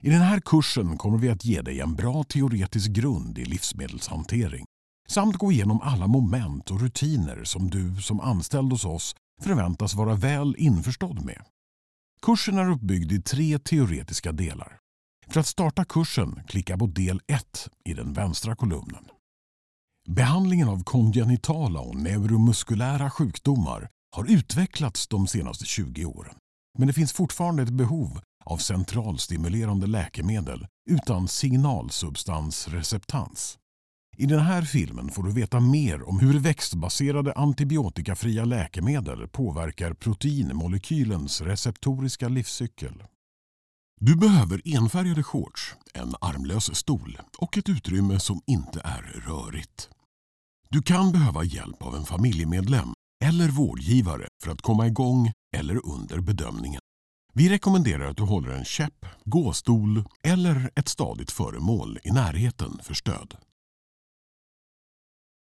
Male
Approachable, Authoritative, Confident, Conversational, Corporate, Deep, Energetic, Engaging, Versatile, Warm
Microphone: Austrian Audio OC18